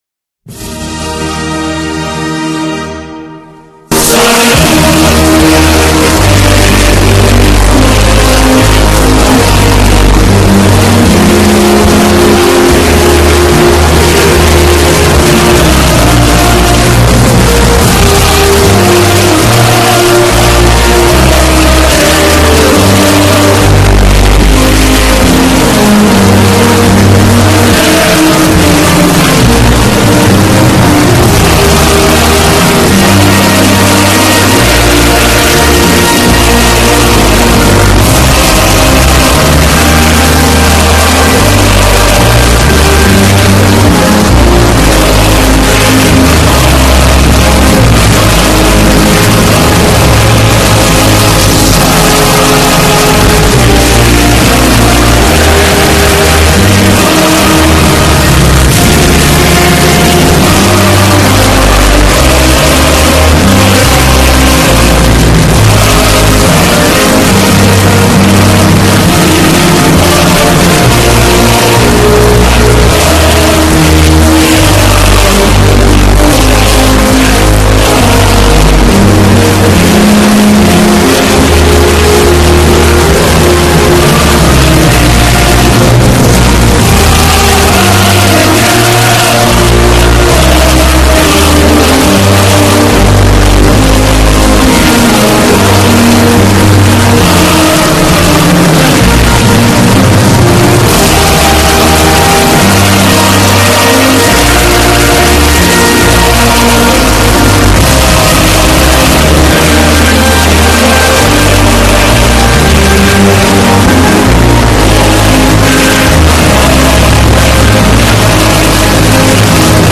what's funny about a terrible audio quality .mp3 of the soviet anthem